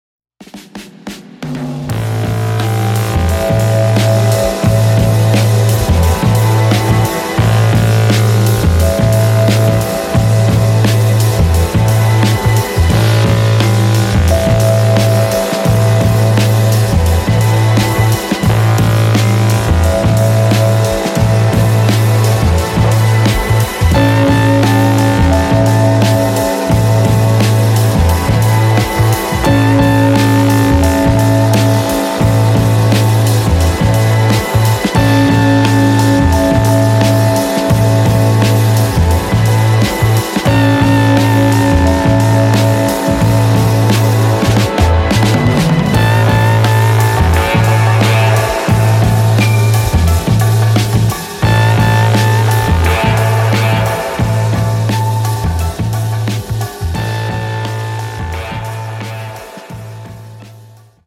psych tinged drum heavy instrumentals